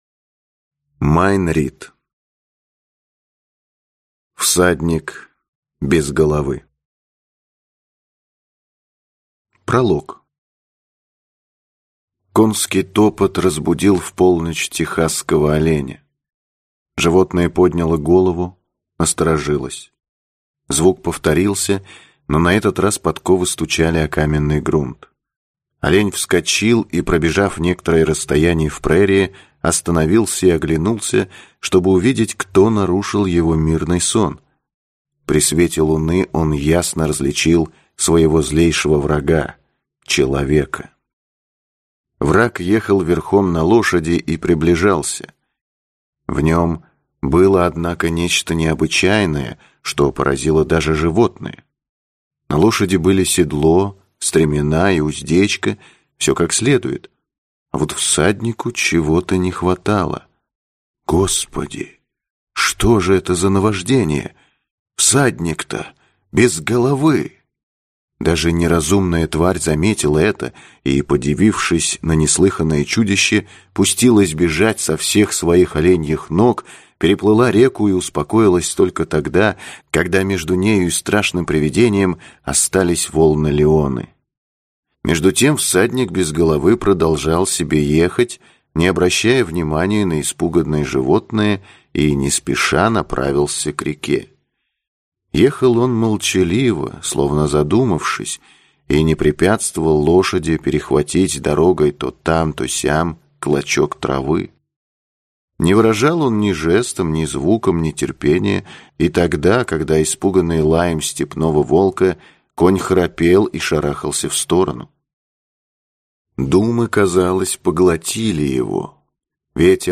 Аудиокнига Всадник без головы | Библиотека аудиокниг